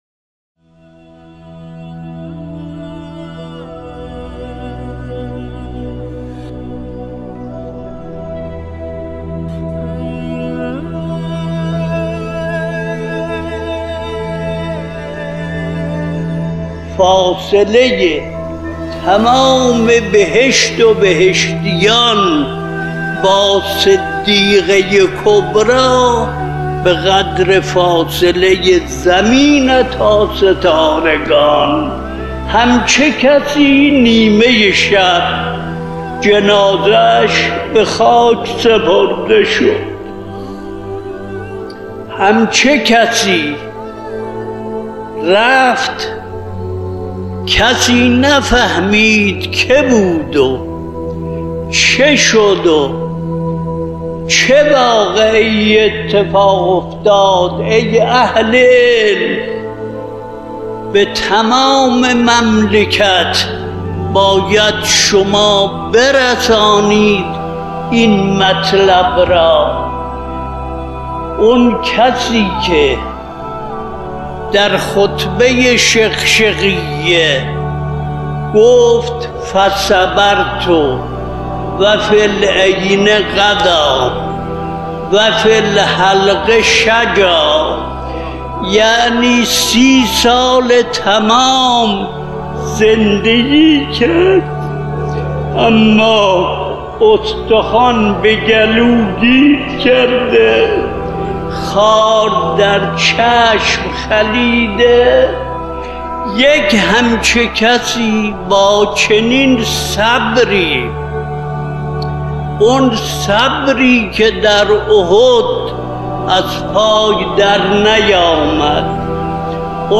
به مناسبت ایام فاطمیه مجموعه پادکست «نگین شکسته» با هدف بیان بندگی و فضائل حضرت فاطمه زهرا(س) با کلام اساتید بنام اخلاق به کوشش ایکنا گردآوری و تهیه شده است، که چهل وهفتمین قسمت این مجموعه با کلام آیت‌الله وحید خراسانی با عنوان «عظمت جایگاه فاطمه زهرا(س)» تقدیم مخاطبان گرامی ایکنا می‌شود.